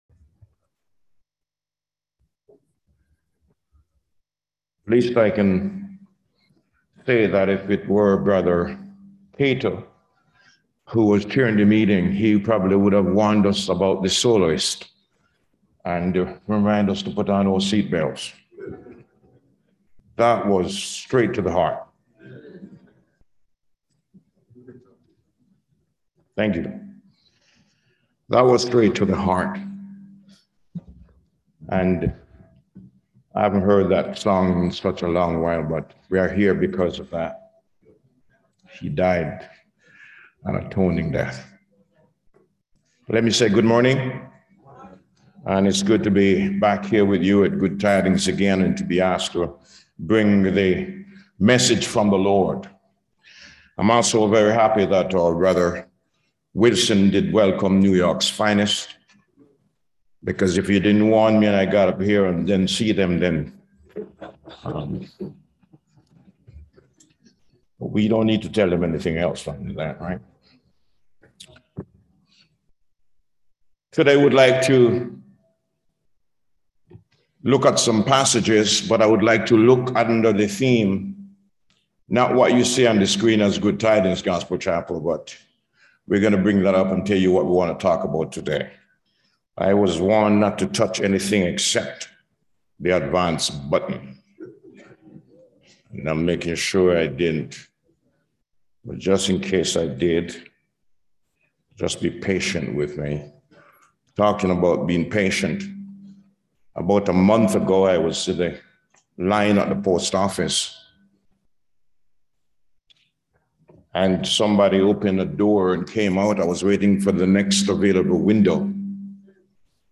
Revelation 4 Service Type: Community Hour The only Man older than His mother Download Files Notes